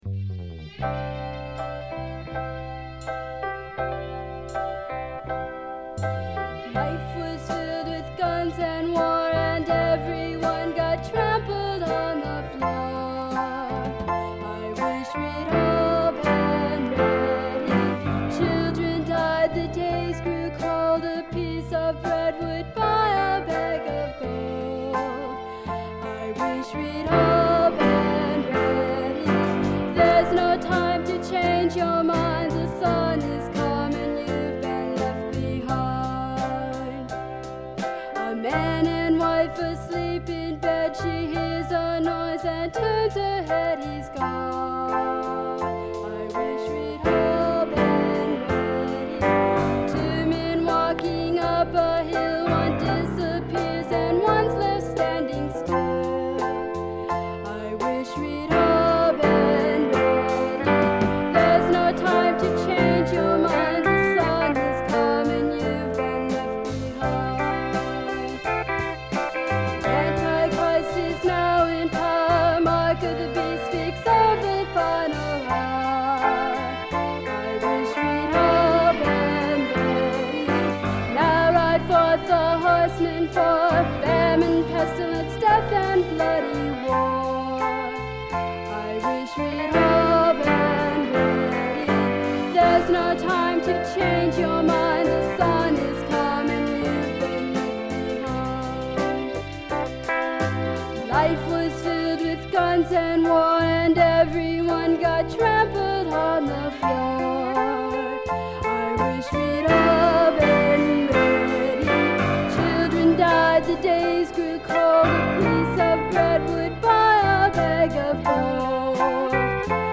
which is why I coined the term “Doom Gospel” to describe it
equally doom-laden